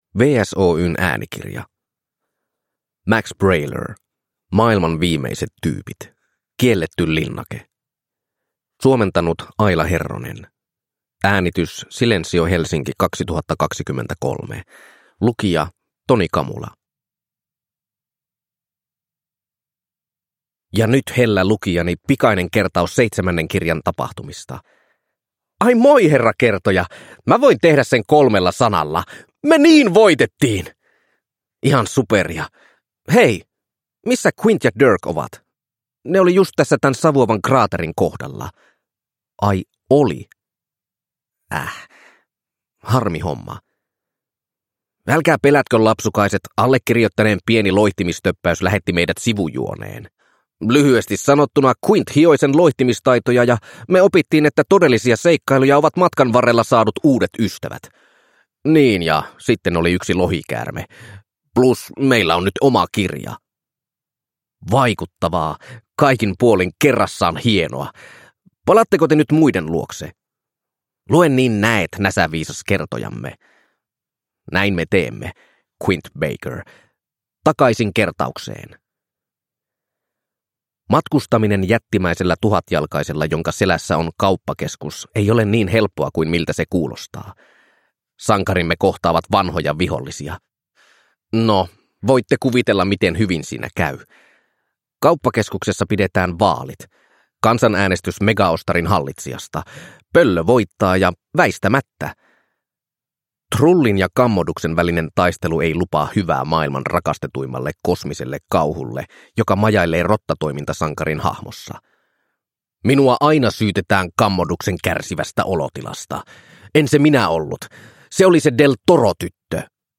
Maailman viimeiset tyypit - Kielletty linnake – Ljudbok – Laddas ner